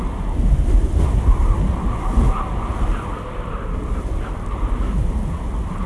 Wind.wav